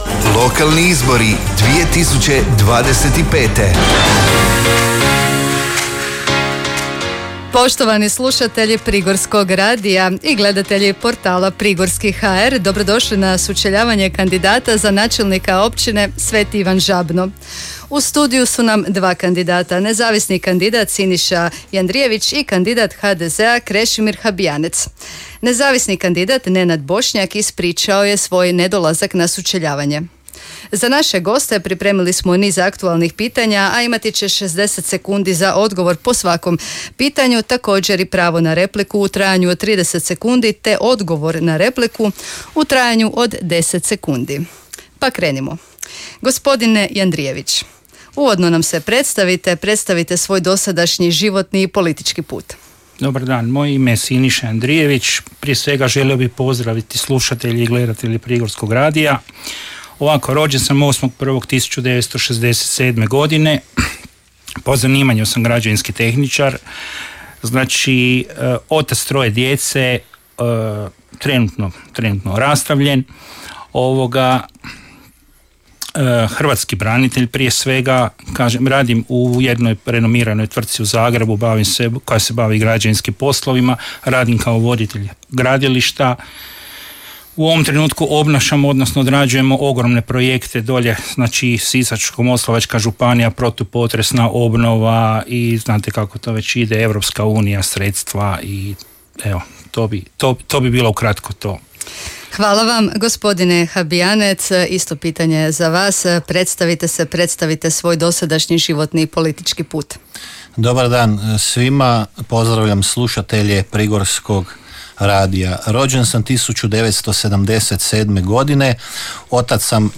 Danas je na Prigorskom radiju održano sučeljavanje kandidata za načelnika Općine Sveti Ivan Žabno.
SUCELJAVANJE-ZABNO.mp3